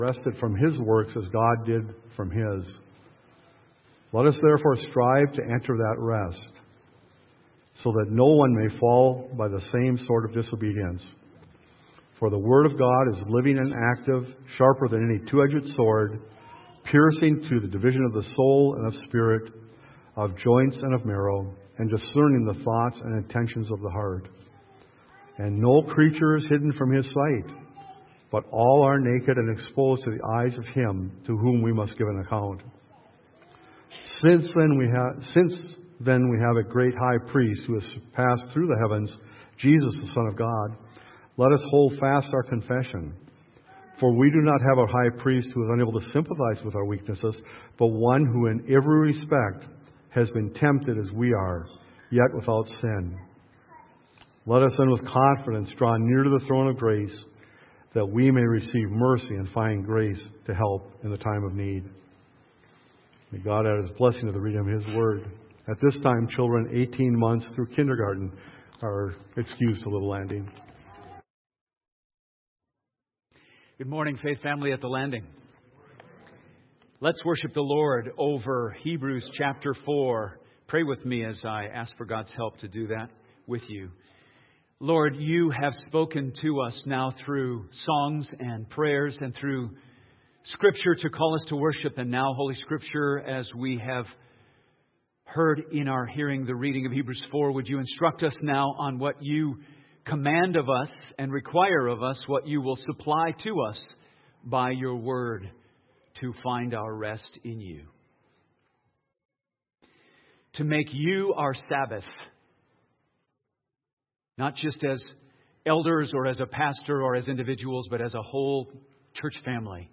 A sermon on resting in Christ